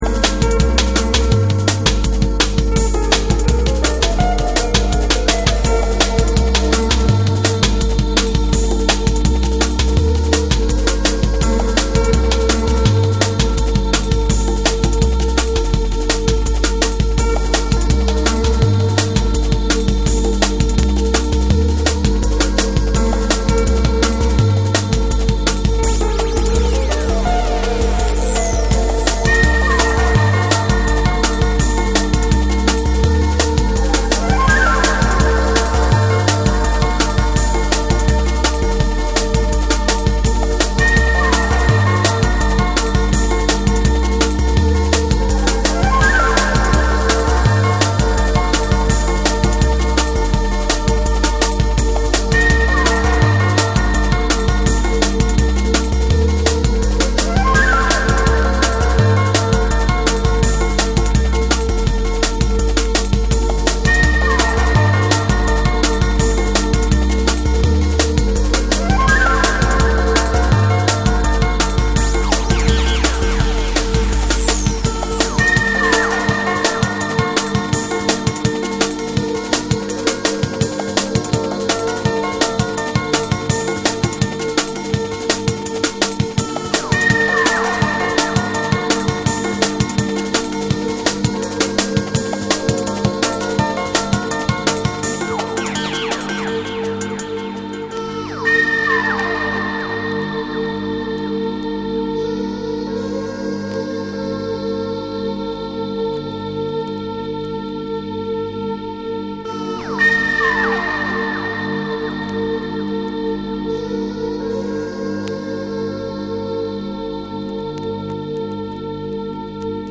ソウルフルなパッドと浮遊感のあるシンセが絡み合うアトモスフェリックなドラムンベースを披露する
透明感と奥深さを兼ね備えた、上質なドラムンベースを収録。
JUNGLE & DRUM'N BASS